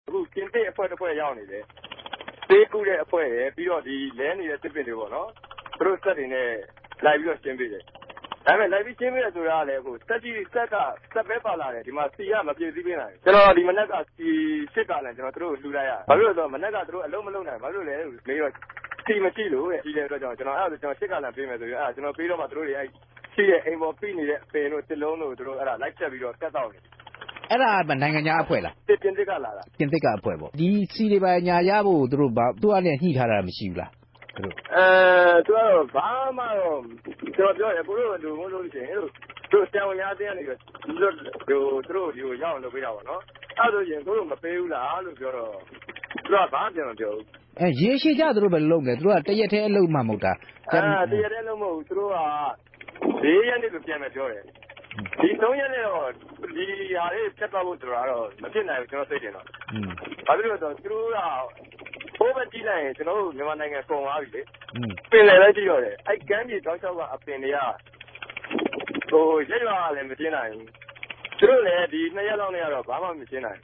ဆက်သြယ် မေးူမန်းခဵက်။